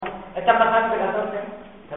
Secciones - Biblioteca de Voces - Cultura oral